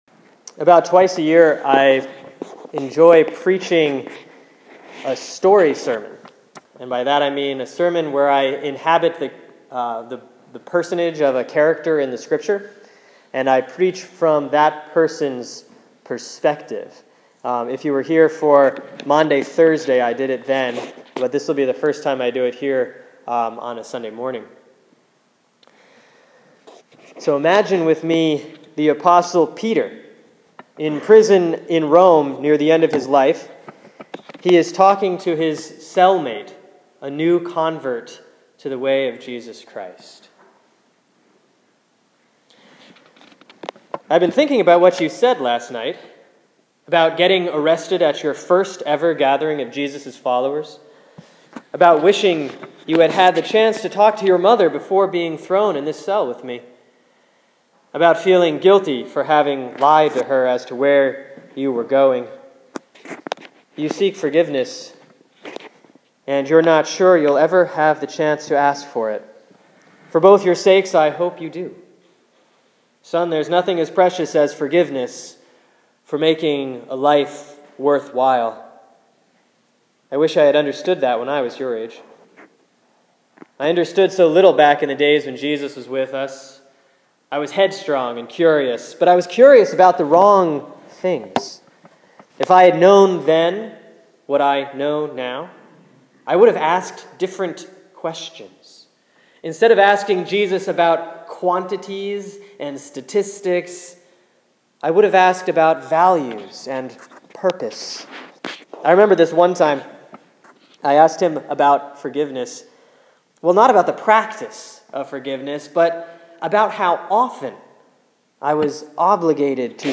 Sermon for Sunday, September 14, 2014 || Proper 19A || Matthew 18:21-35